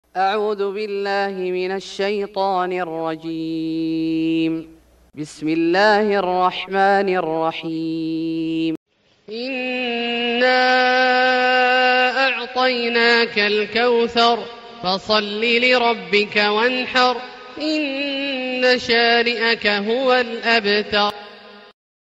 سورة الكوثر Surat Al-Kauthar > مصحف الشيخ عبدالله الجهني من الحرم المكي > المصحف - تلاوات الحرمين